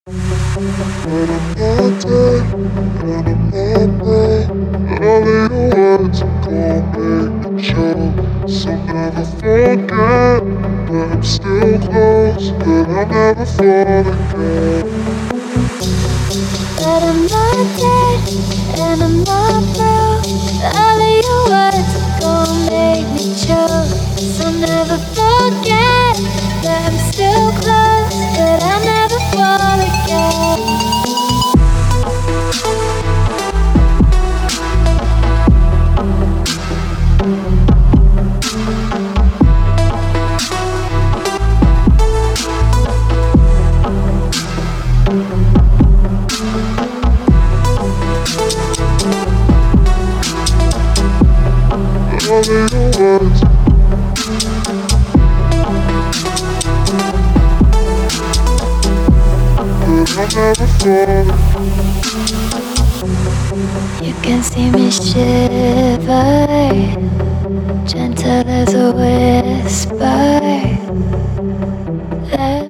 • Качество: 128, Stereo
спокойные
chillout
романтическая тема
Chillout композиция